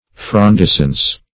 Search Result for " frondescence" : The Collaborative International Dictionary of English v.0.48: Frondescence \Fron*des"cence\, n. (Bot.)